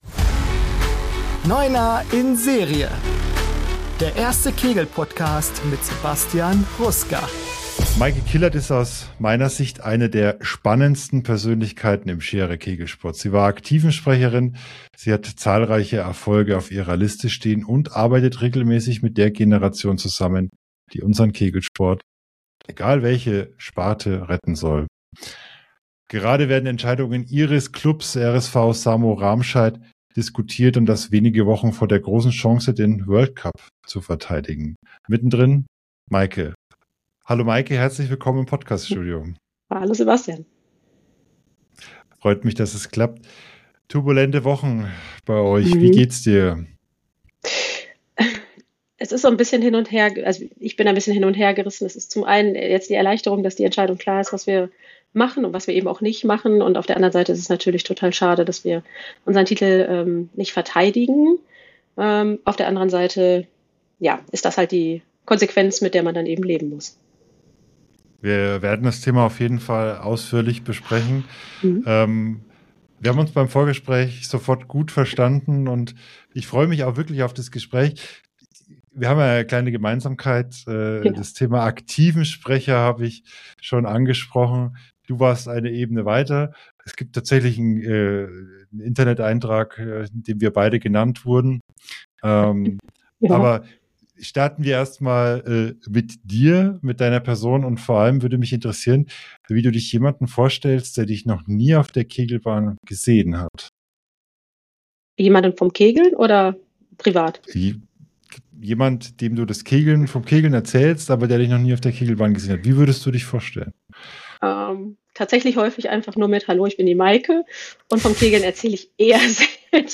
**Neuner in Serie** ist ein Kegel-Podcast.